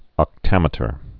(ŏk-tămĭ-tər) also oc·tom·e·ter (-tŏm-)